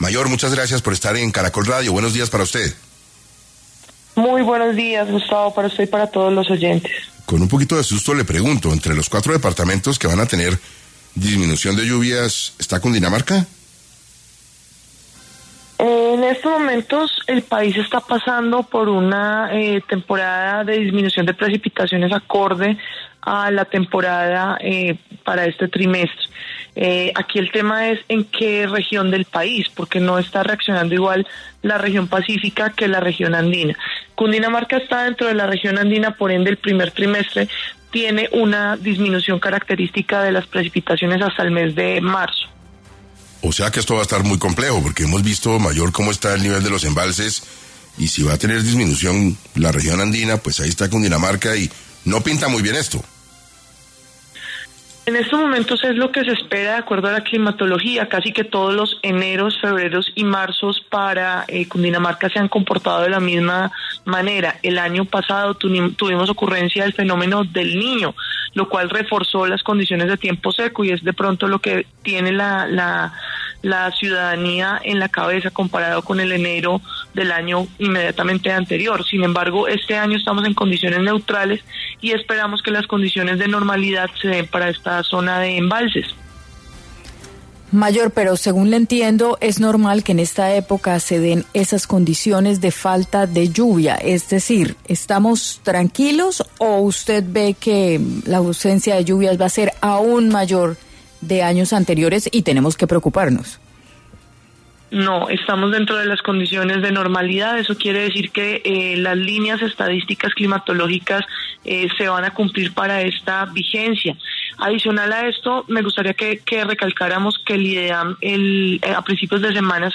En 6AM de Caracol Radio estuvo la mayor Carolina Rueda, jefa del IDEAM, para explicar si disminuirán las lluvias durante el primer trimestre del año y cuáles serán los departamentos que sí tendrán precipitaciones.